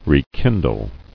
[re·kin·dle]